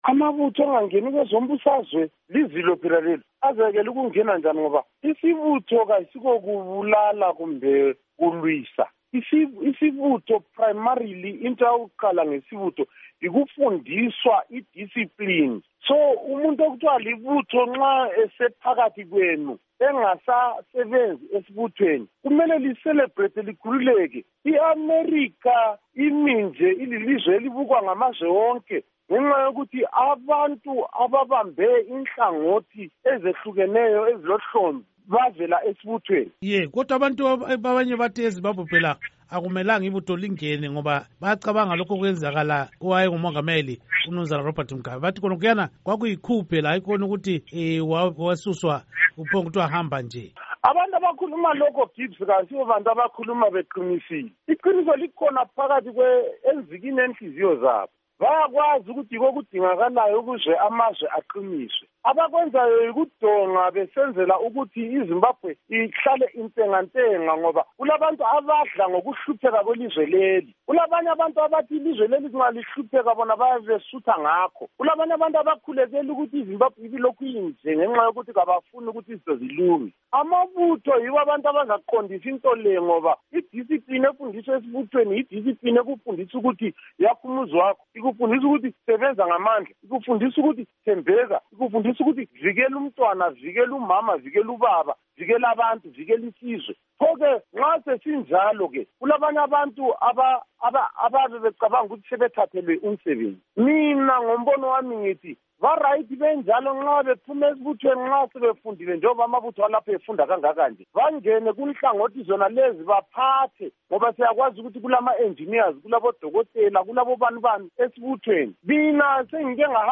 Ingxoxo Esiyenze LoMnu. Dingumuzi Phuthi